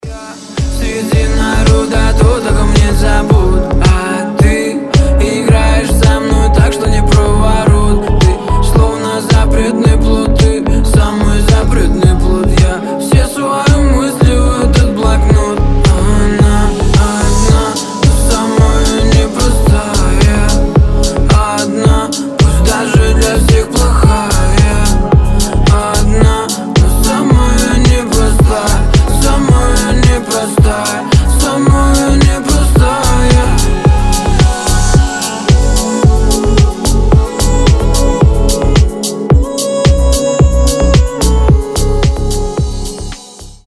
• Качество: 320, Stereo
поп
мелодичные
лирические